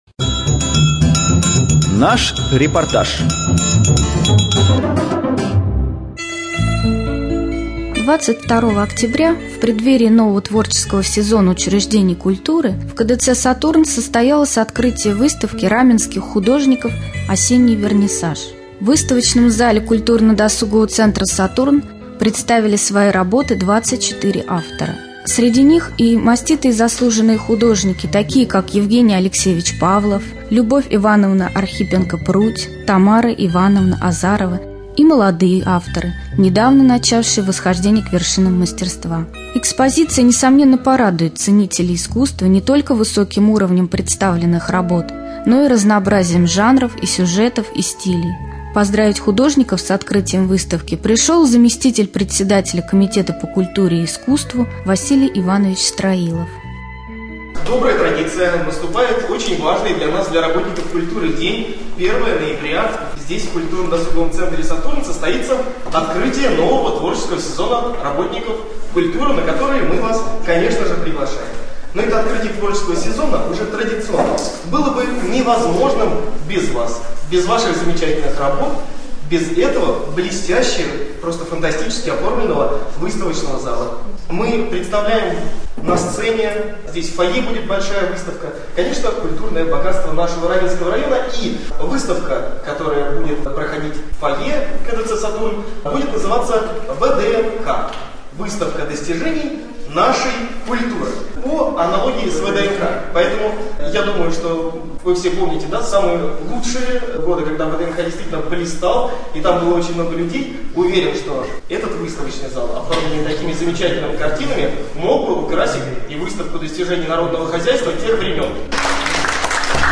4.Рубрика «Специальный репортаж ». В выставочном зале КДЦ Сатурн открылась выставка Раменских художников «Осенний вернисаж».